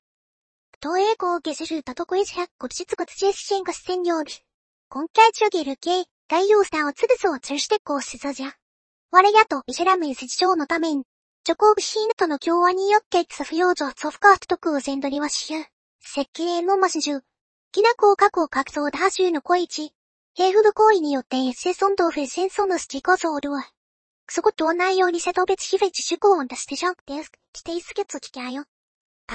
特に「おまけ：WAV（+12dB増幅＆高音域削減」のトレーニング結果の場合、何か中国語っぽいようにも聞こえる、意味のない音声になってしまいます。
training_03_convert.mp3：「おまけ：WAV（+12dB増幅＆高音域削減）」のトレーニング結果を元にして、convert styleにチェックを入れた例
何かよくわからない言葉で喋りだした。こわい